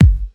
Kick
Original creative-commons licensed sounds for DJ's and music producers, recorded with high quality studio microphones.
round-bass-drum-single-hit-g-sharp-key-829-8Eu.wav